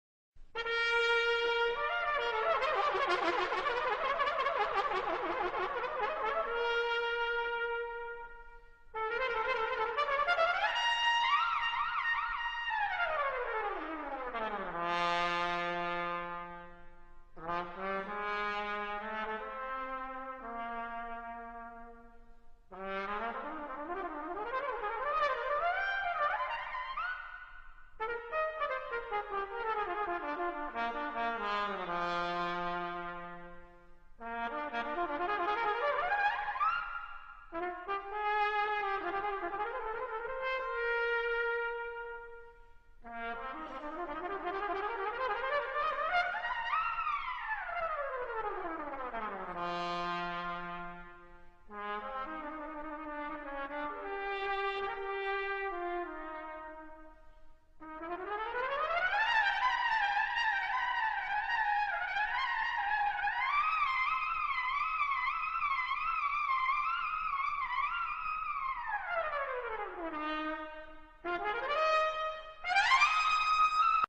TROMPETA (viento metal)